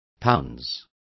Complete with pronunciation of the translation of pounce.